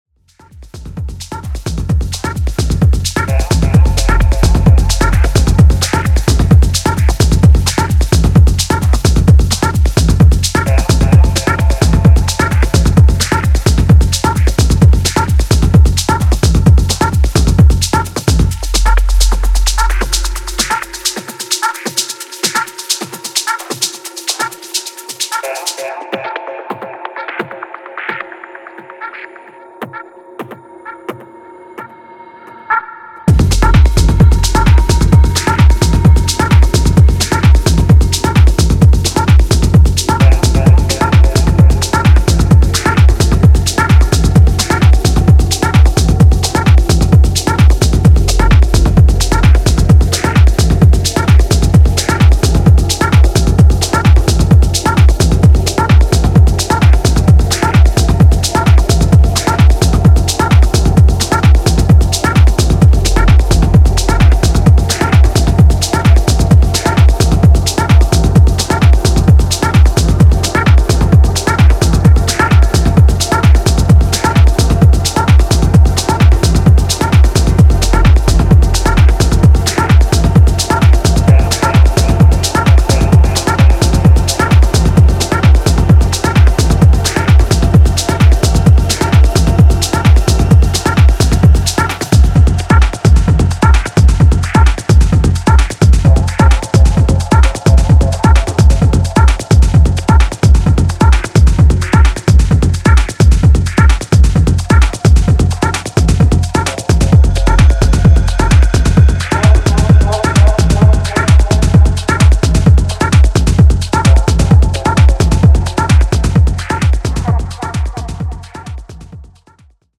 A commitment to a tension-building groove
Dub Techno , Dubstep